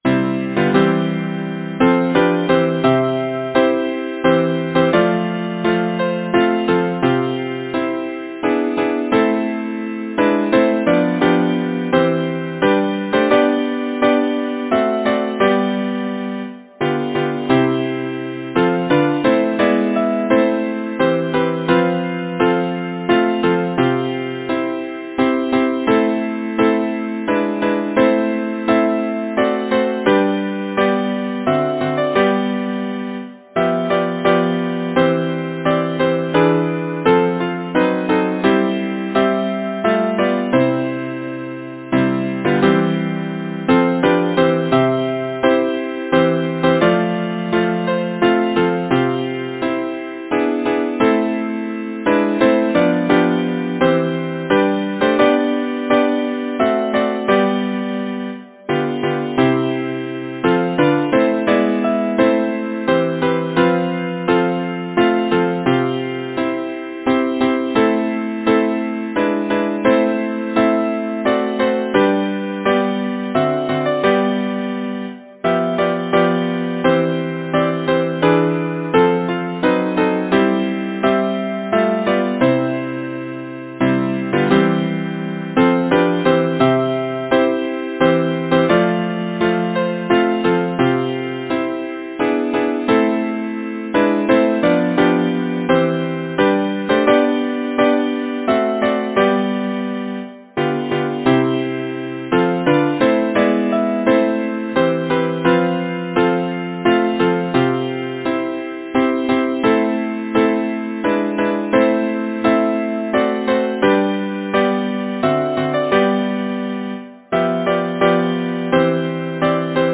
Title: May Composer: Edward Stroud Lyricist: Emily Chubbuck Number of voices: 4vv Voicing: SATB Genre: Secular, Partsong
Language: English Instruments: A cappella